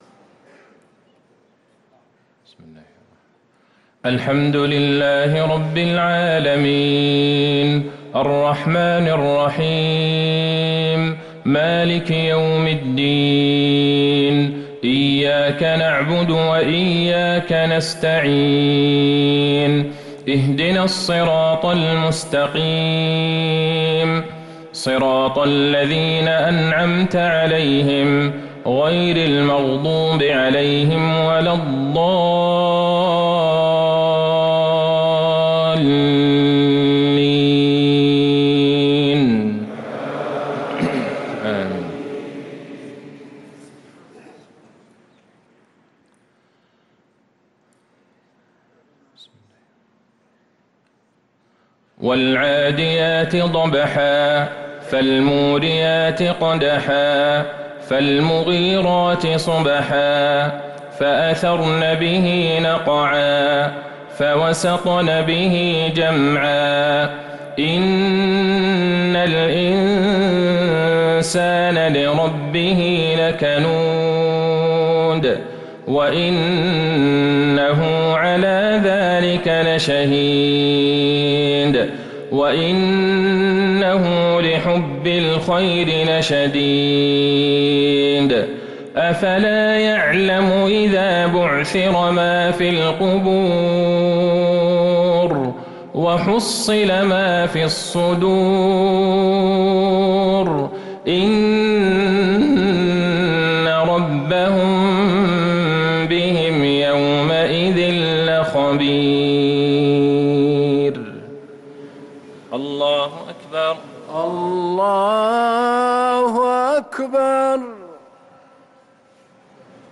صلاة المغرب للقارئ عبدالله البعيجان 13 ربيع الآخر 1445 هـ